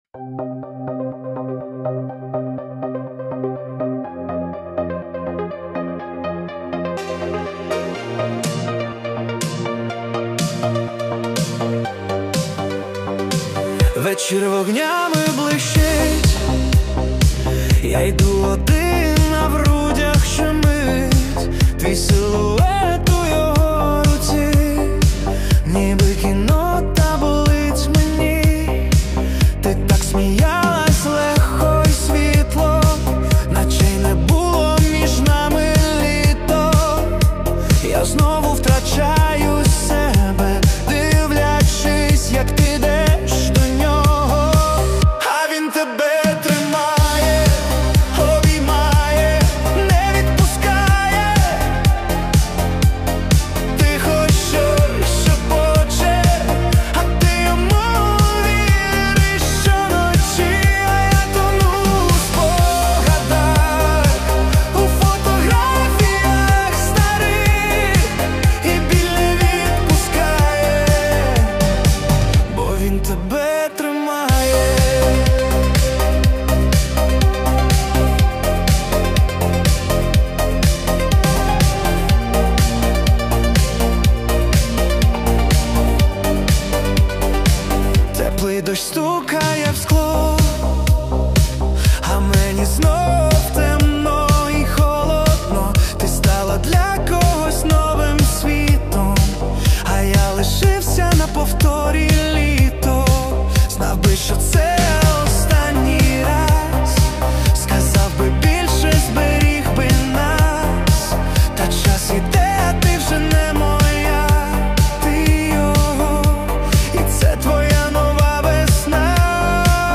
Аранжування: AI
Всі мінусовки жанру AІ music
Плюсовий запис